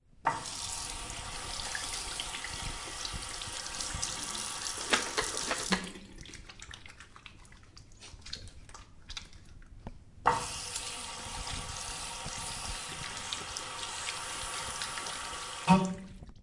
描述：它是在Pompeu Fabra的Tallers大楼的浴室里用H202录音机以中等增益录制的。从水龙头流出的水和手上的肥皂发出的轻松的声音，
Tag: 洗手 肥皂 校园UPF UPF-CS13 TALLERS